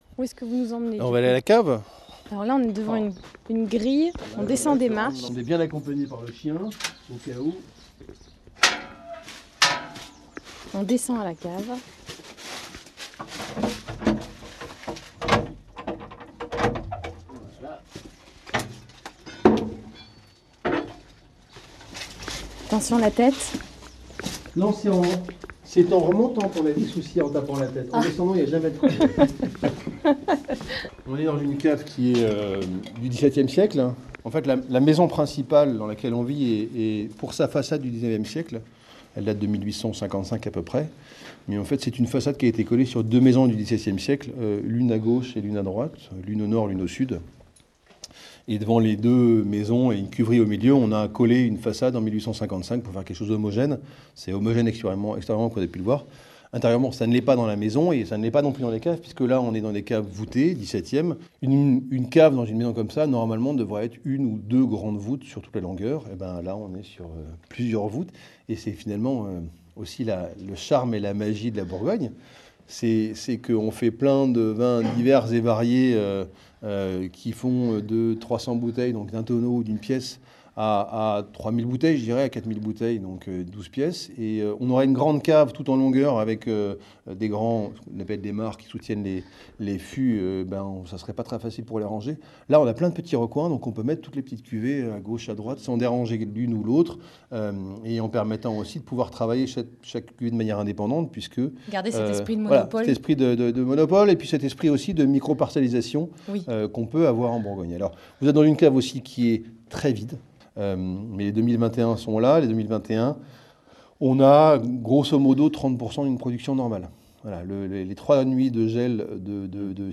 Vendredi 25 février 2022, nous accueillions France Bleu Bourgogne au Domaine : un reportage à écouter pour découvrir notre nouveau projet…